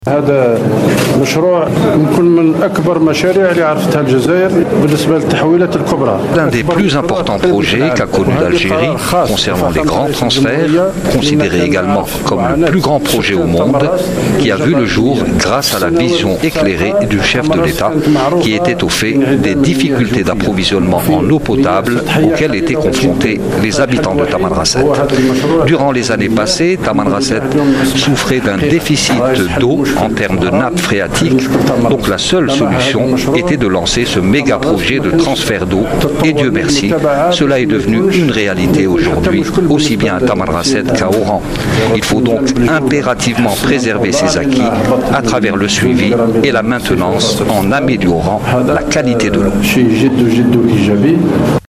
envoyé spécial de la radio Chaine 3 Déclaration de Sellal lors de l'inauguration de la station de déminéralisation de l’eau Abdelmalek Sellal inaugure plusieurs réalisations à In-Salah